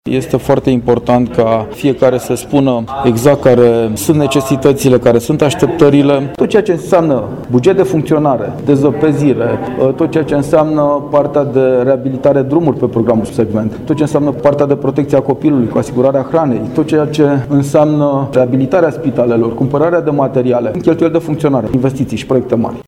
La sediul Consiliului Județean Brașov a avut loc dezbarerea publică privind Proiectul de buget al județului Brașov pe anul 2018 și estimări pentru anii 2019-2021.
Bugetul județului Brașov este diminuat cu 90 de milioane de lei, comparativ cu anul anterior, lucru prezentat în startul dezbaterii de președintele instituției, Adrian Veștea, care a subliniat și principalele aspecte, legate de componența bugetului pe 2018: